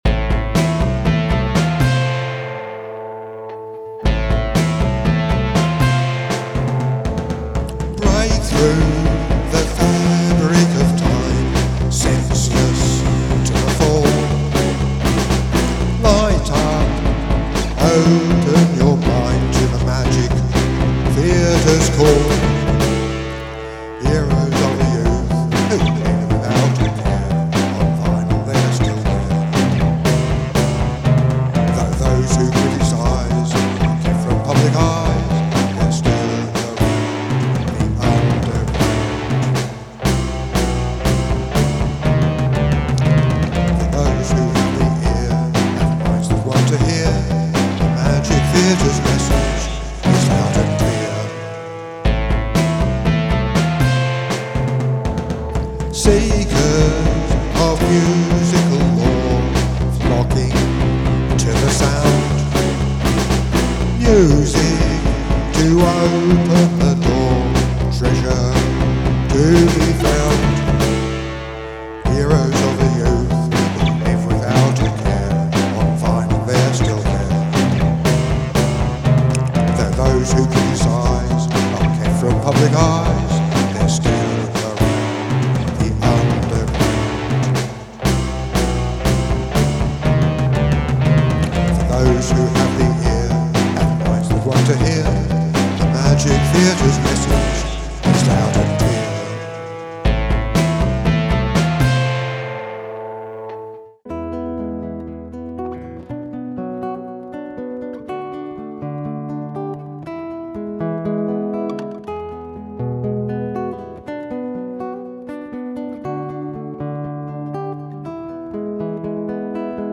magic-theatre-vocal-3.mp3